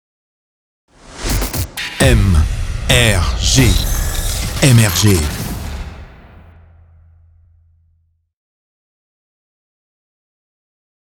Production de jingles
Ils sont réalisés et produits par nos équipes en interne.